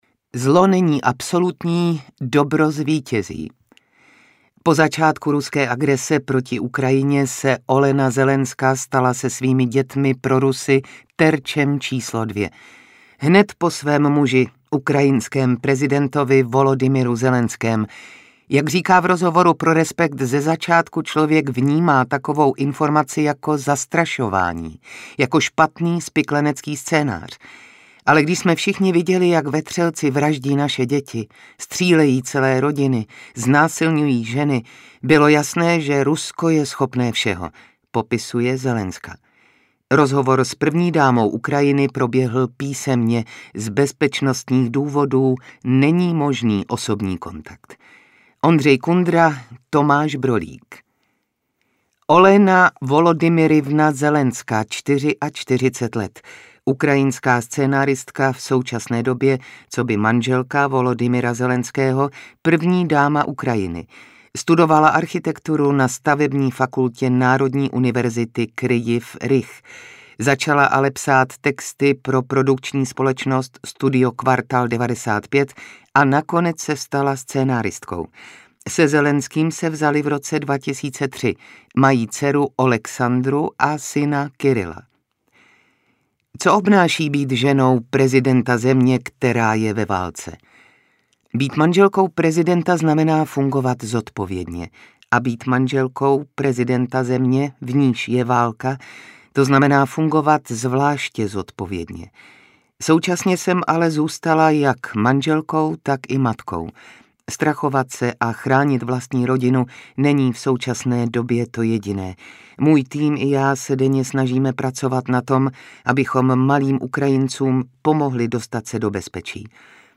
Nahráno ve společnosti 5Guests.
Respekt 15/2023– Audiotéka ve spolupráci s českým týdeníkem Respekt představuje Respekt v audioverzi.